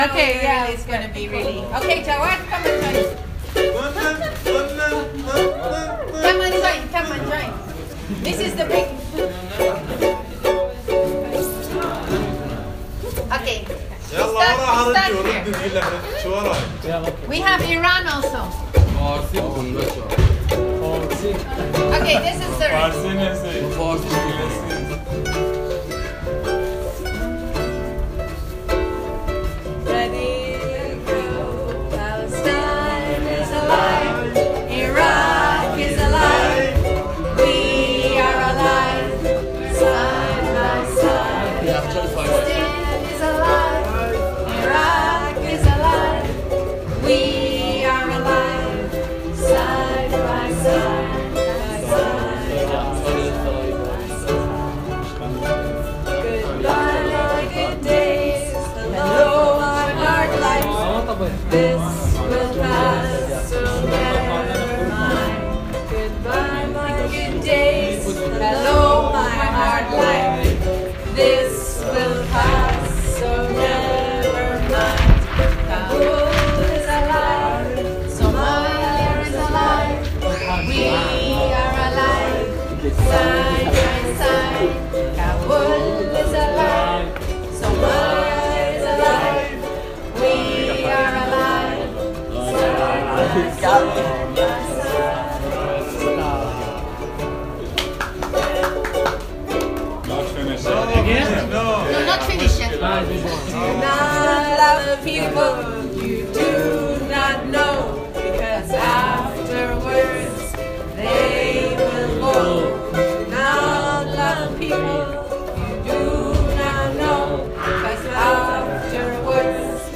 The song became a kind of chant that we sang together, including new countries each time we repeated the phrases.